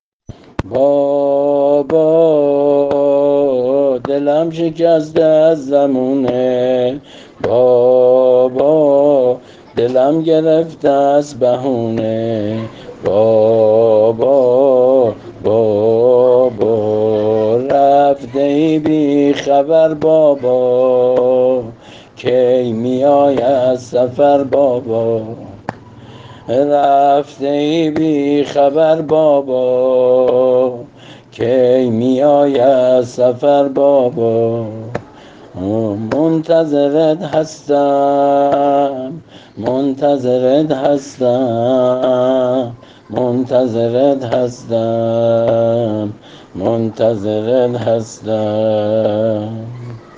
◾نوحه سینه‌زنی